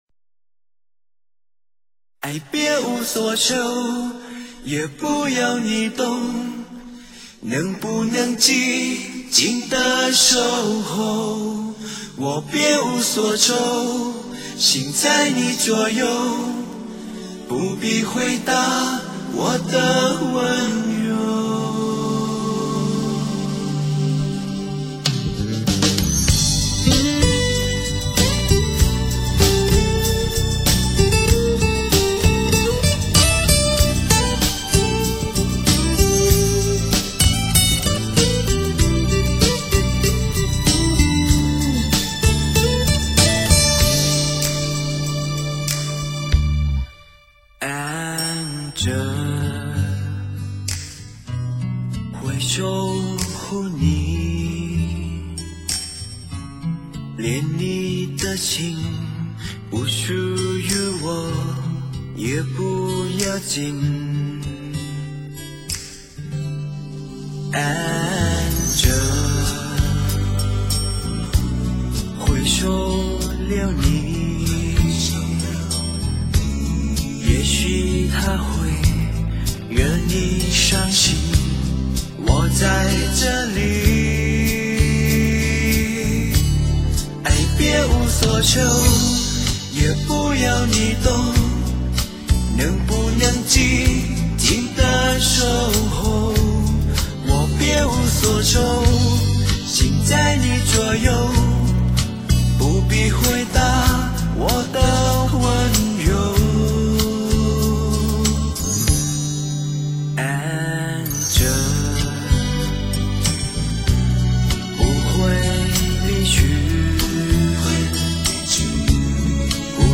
重新编曲之后，与原味大不相同
更显得清纯动人
虽然国语差了一点